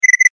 PDA_Message_sms.mp3